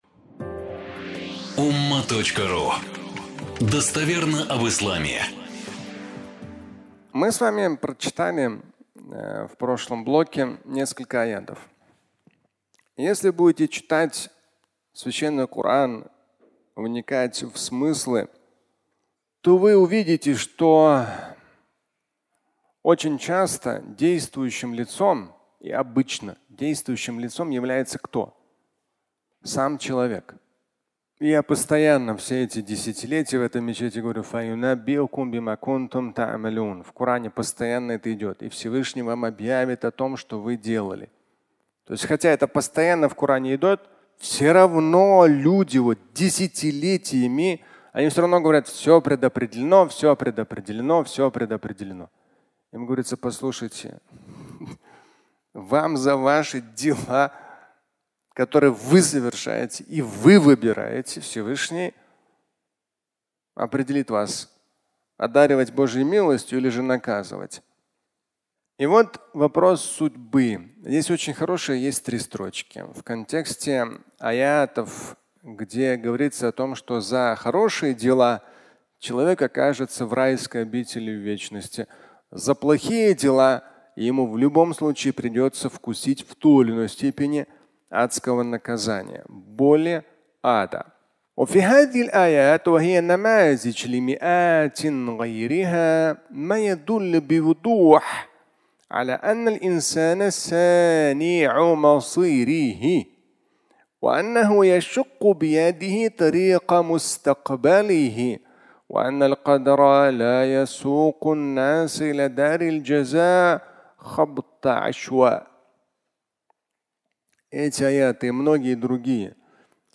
"Судьба" (аудиолекция)
Фрагмент пятничной лекции